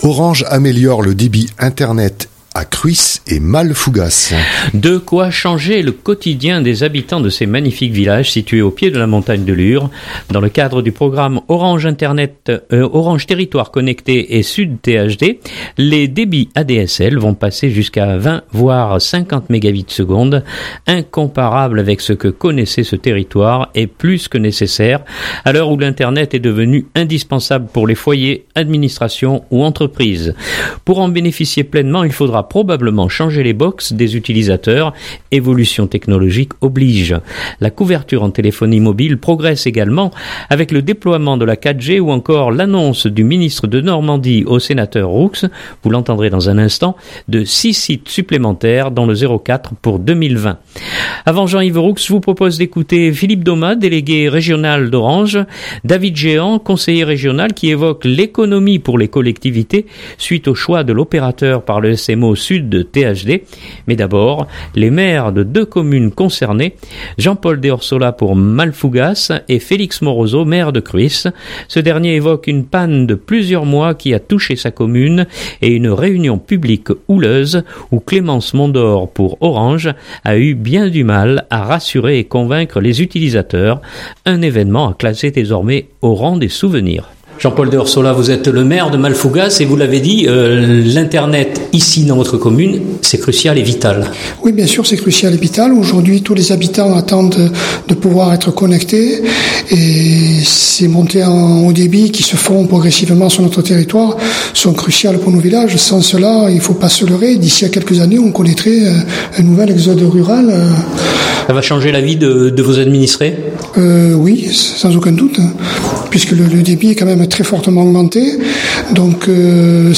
La couverture en téléphonie mobile progresse également avec le déploiement de la 4G ou encore l’annonce du ministre De Normandie au sénateur Roux – vous l’entendrez dans un instant – de 6 sites supplémentaires dans le 04 pour 2020.
Mais d’abord les maires de deux communes concernées, Jean-Paul Déorsola pour Mallefougasse et Félix Moroso, maire de Cruis.